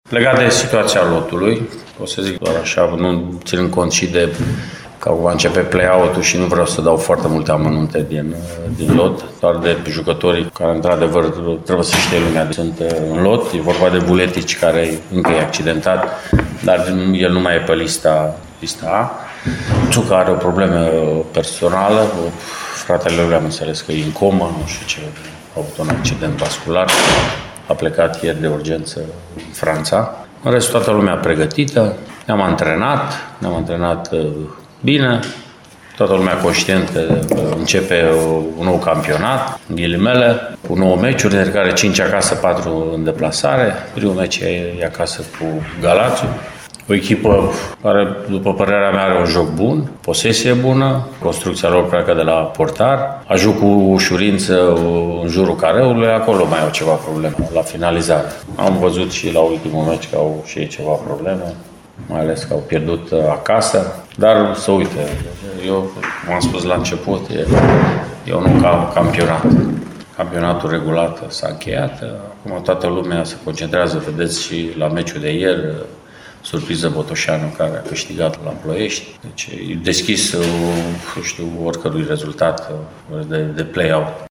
În lotul arădenilor a apărut o problemă de ultim moment, despre care vorbește antrenorul Mircea Rednic: